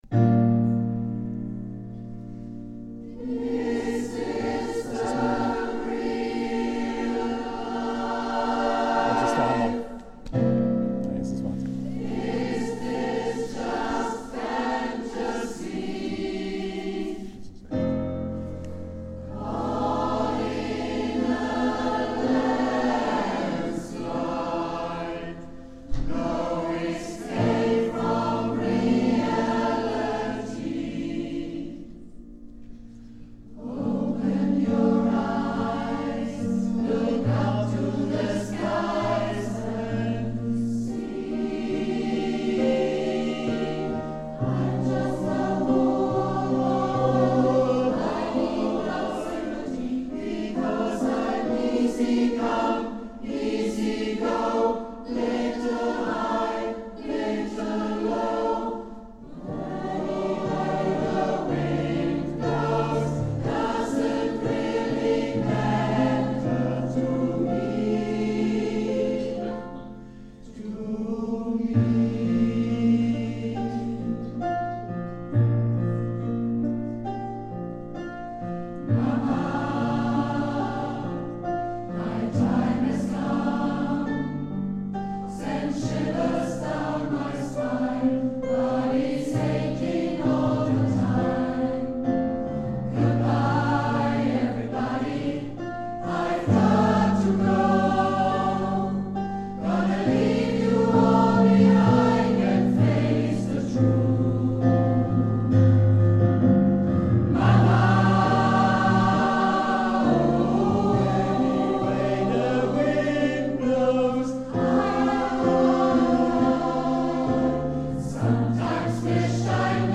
09 - Konzertaufnahmen - ChorArt zwanzigelf - Page 3
Wir sind laut, leise, kraftvoll, dynamisch, frisch, modern, bunt gemischt und alles, nur nicht langweilig!